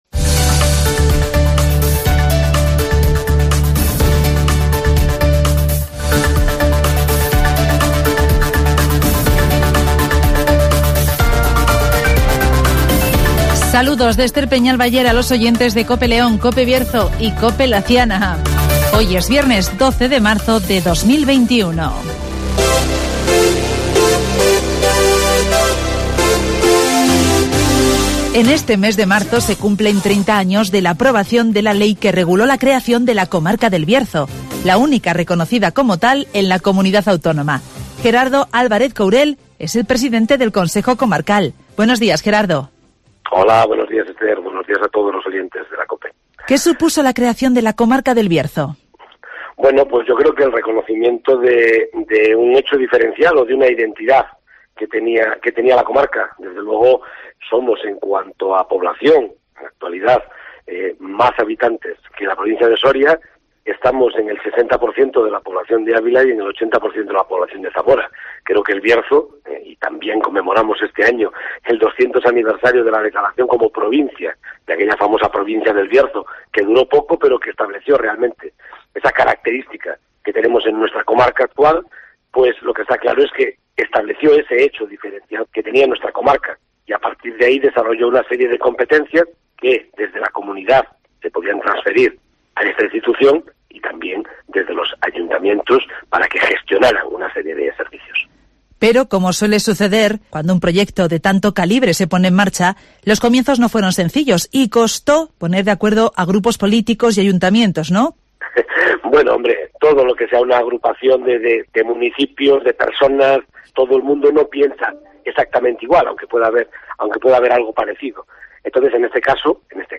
Se cumplen 30 años de la aprobación de la Ley que reguló la creación de la comarca del Bierzo (Entrevista a Gerardo Álvarez Courel, pte del Consejo Comarcal